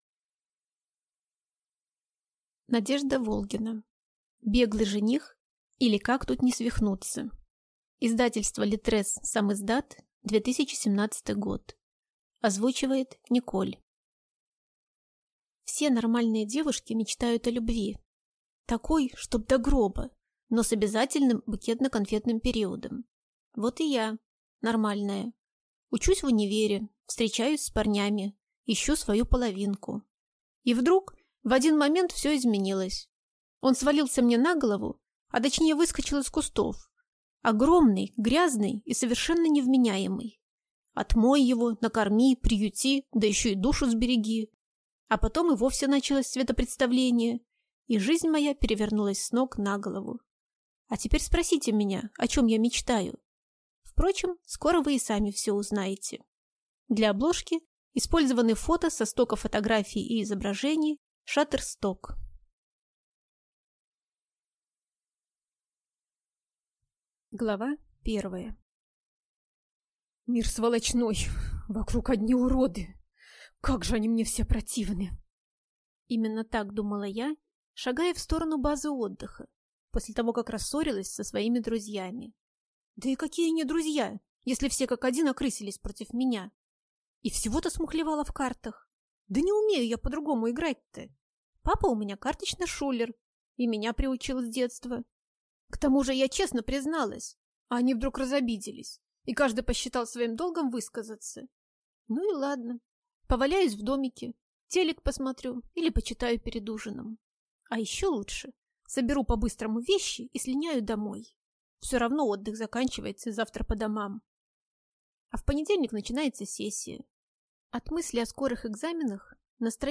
Аудиокнига Беглый жених, или Как тут не свихнуться | Библиотека аудиокниг